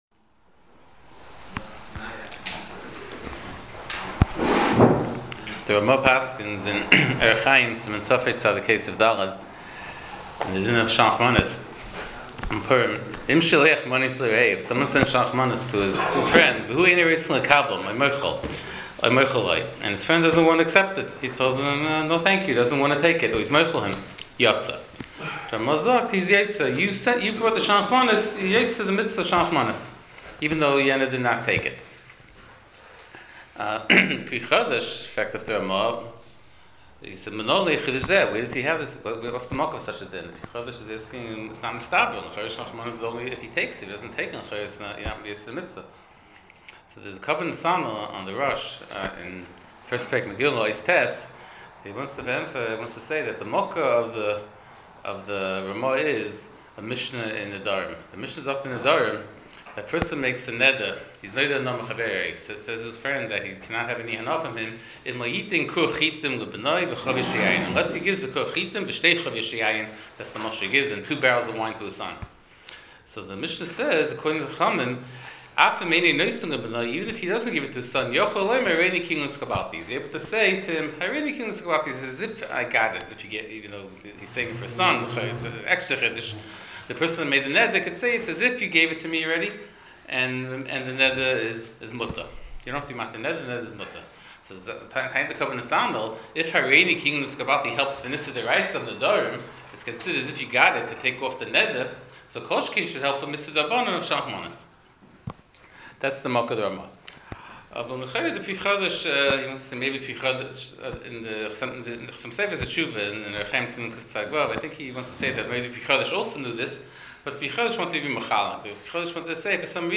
Shiur provided courtesy of Madison Art Shop.